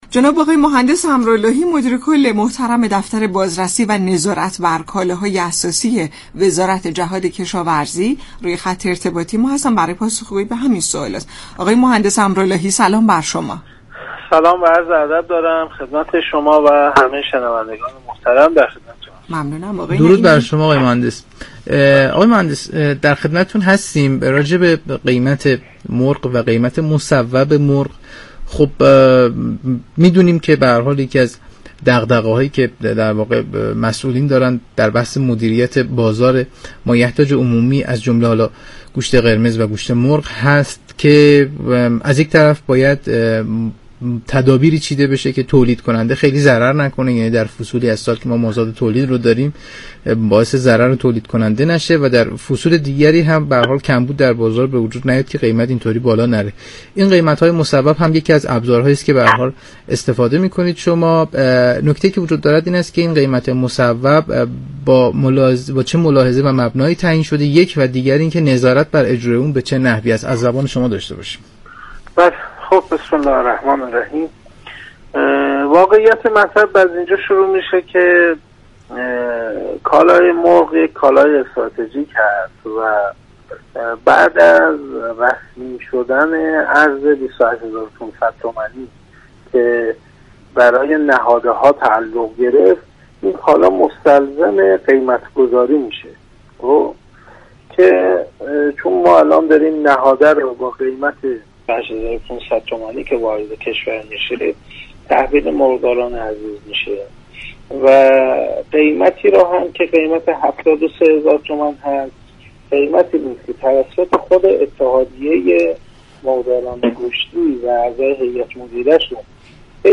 به گزارش شبكه رادیویی ایران، مسعود امراللهی مدیر كل دفتر بازرسی و نظارت بر كالای اساسی وزارت جهاد كشاورزی در برنامه «نمودار» درباره جزئیات قیمت مصوب مرغ گفت:مرغ به عنوان یك كالای استراتژیك پس از رسمی شدن ارز 28 هزار و 500 تومان برای نهاده های دامی؛ مستلزم قیمت گذاری شده است.